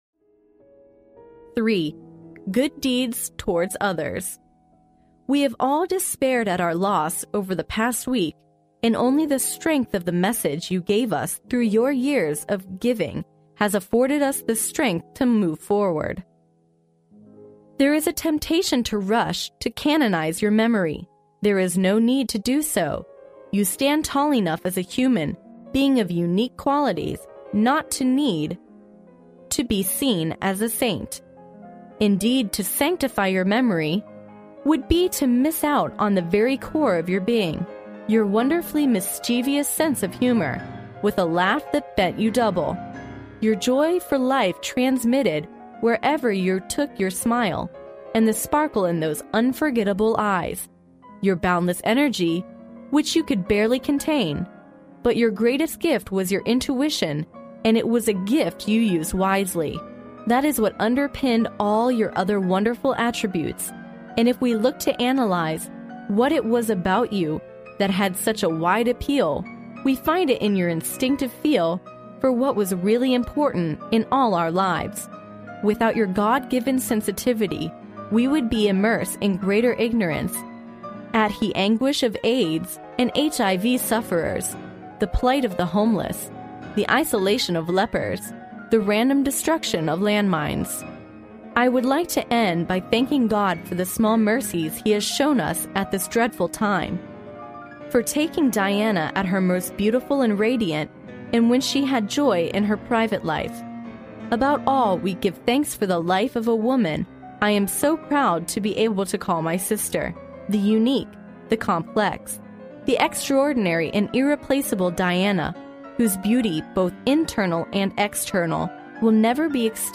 历史英雄名人演讲 第107期:查尔斯·斯宾塞在戴安娜的葬礼上的致辞:与人为善 听力文件下载—在线英语听力室